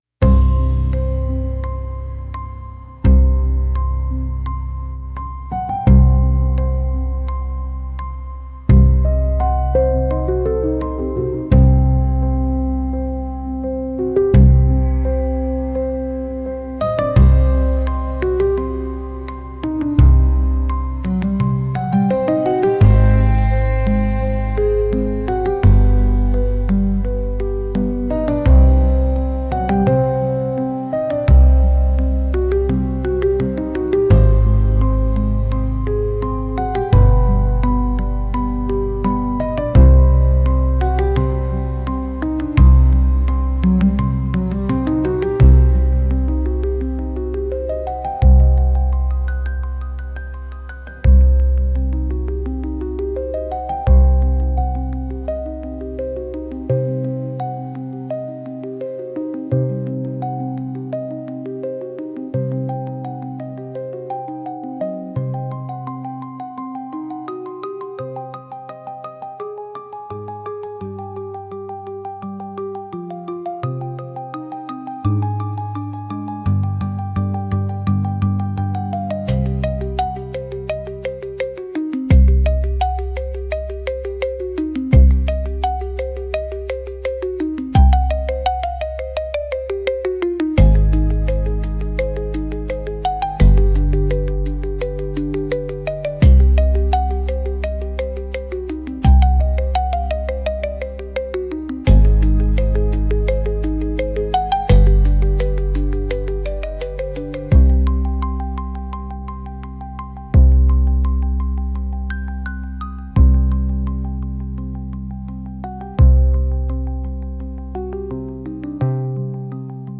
Instrumental- Whispers of the Forgotten- 2.18 mins